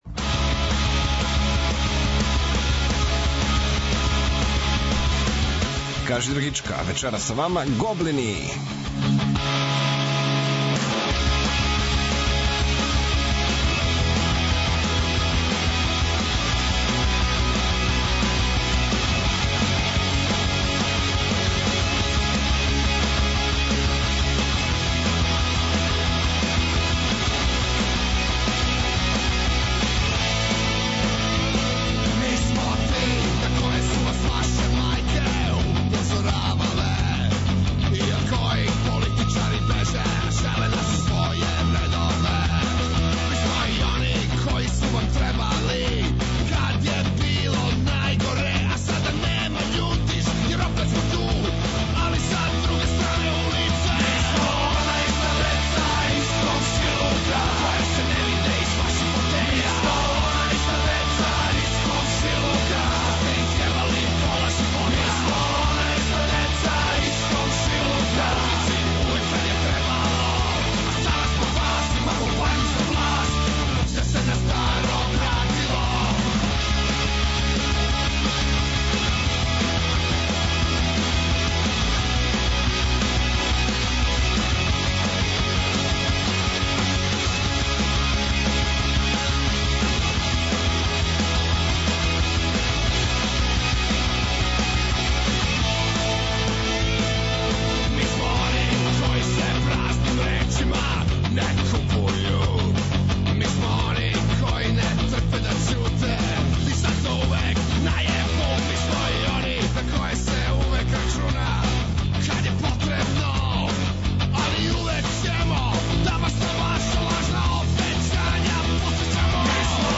Најавиће предстојеће наступе Гоблина, а слушаћемо и нова издања домаћих бендова, која су му запала за ухо.